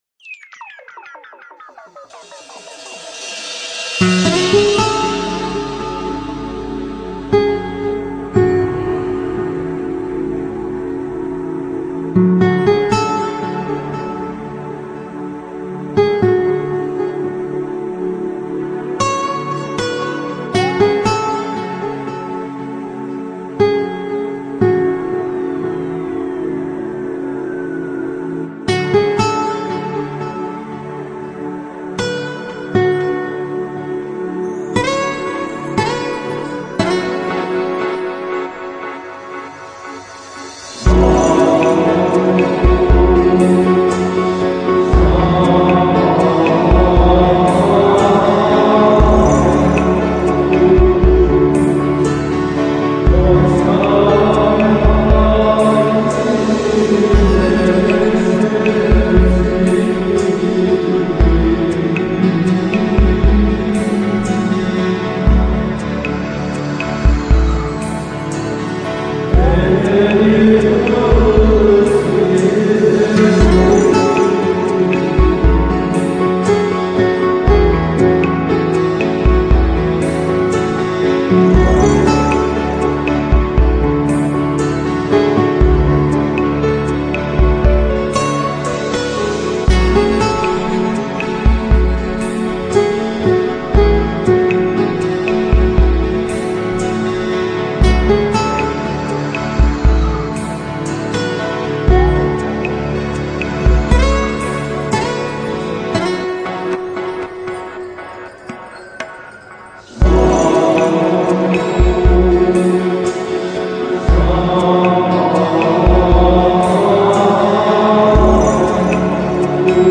Chill Out、Lounge 音乐